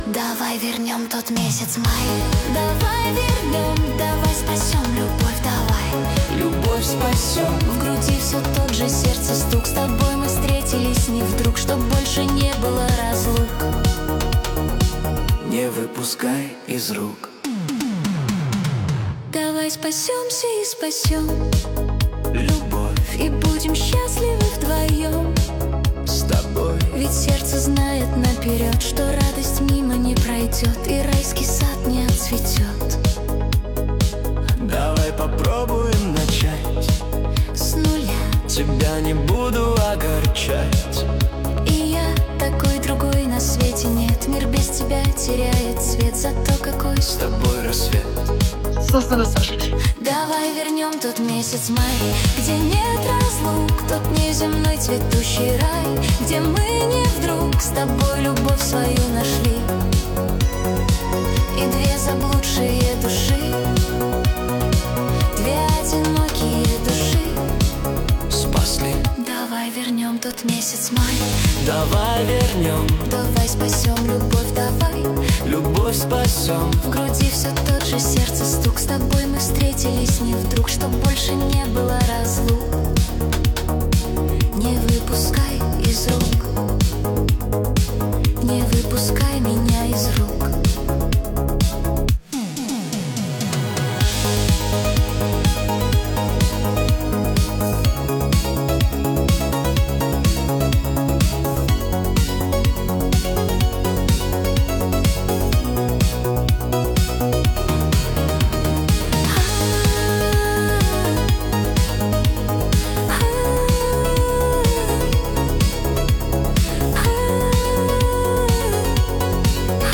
Качество: 320 kbps, stereo
нейросеть ИИ песня